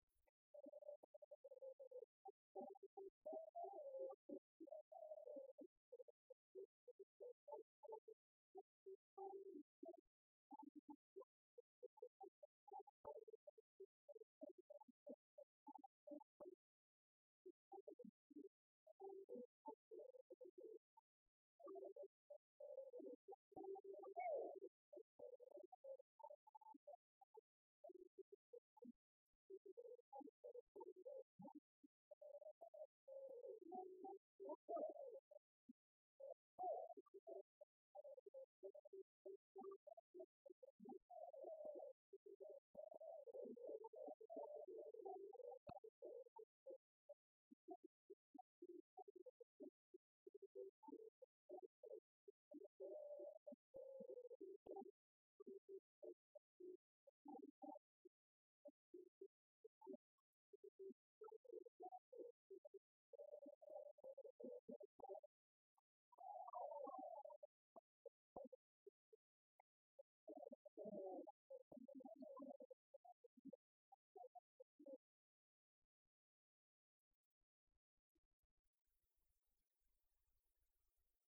danse : spirou
Chansons du Club des retraités
Pièce musicale inédite